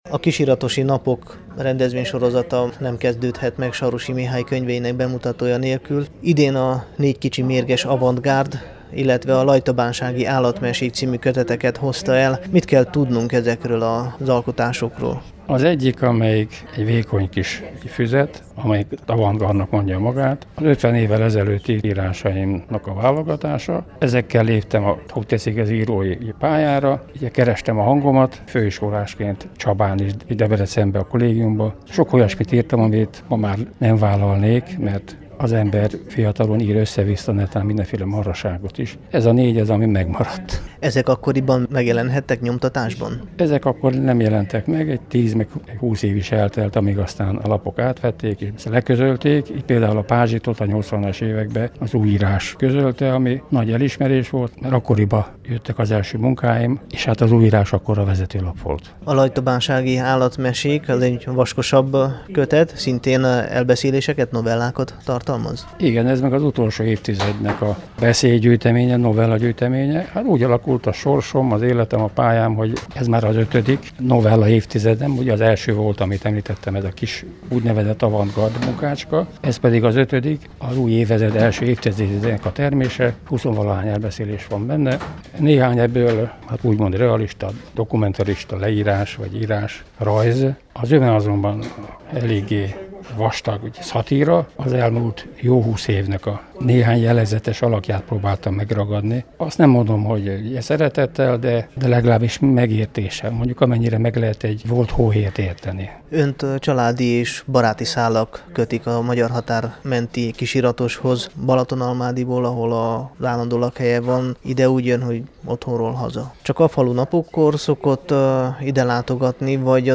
készített összeállítást a könyvbemutató után a Temesvári Rádió számára.